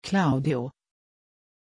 Aussprache von Claudio
pronunciation-claudio-sv.mp3